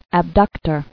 [ab·duc·tor]